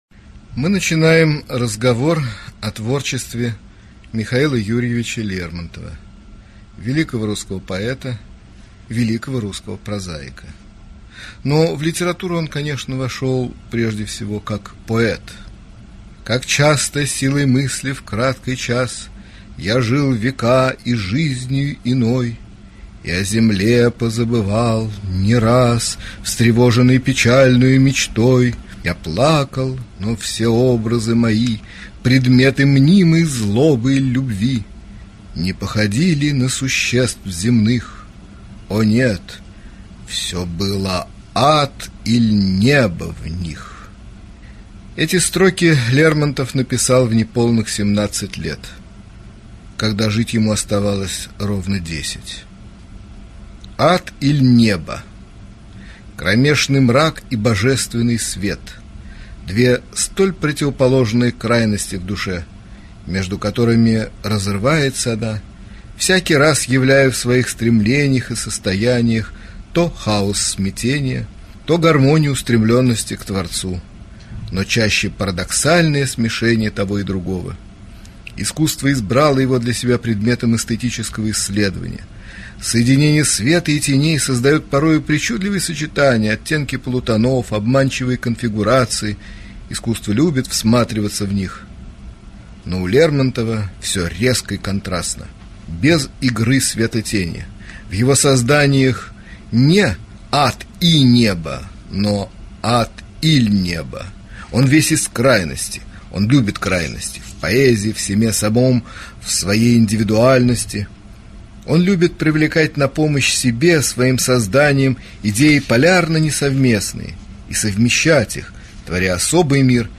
Аудиокнига Лекция